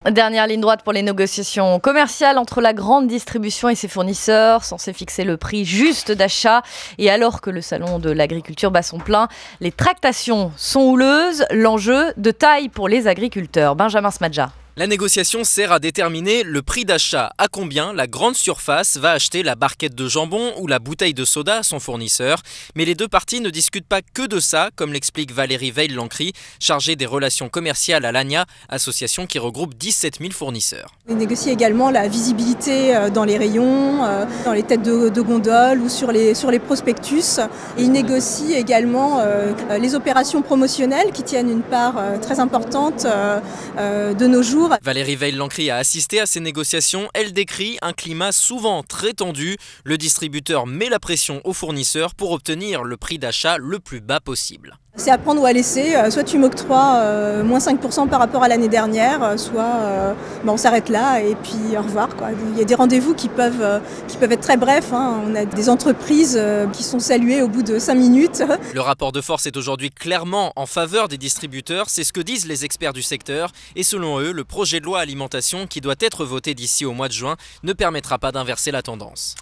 > 01 mars 2018 – RMC : Les distributeurs mettent la pression sur les entreprises pour obtenir des prix bas.  Interview